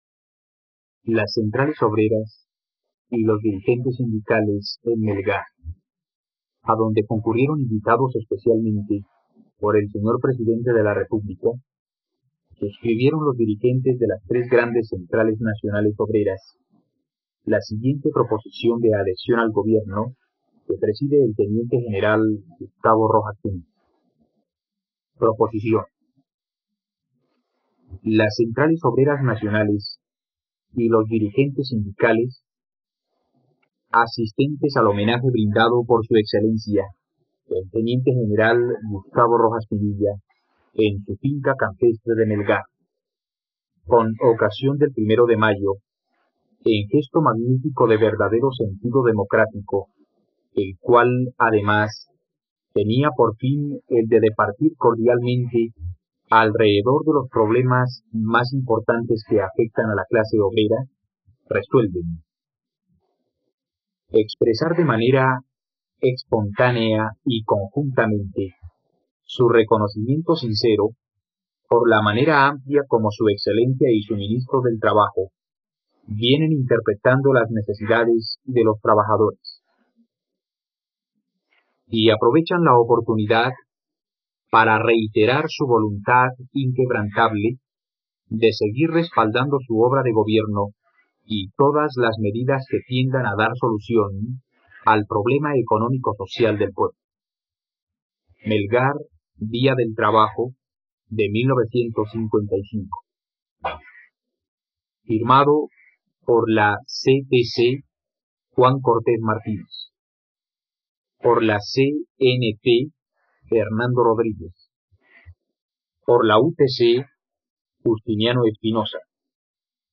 Discursos: Día del Trabajo | RTVCPlay
..Escucha ahora el discurso del general Gustavo Rojas Pinilla en el Día del Trabajo, el 10 de mayo de 1955, en la plataforma de streaming RTVCPlay.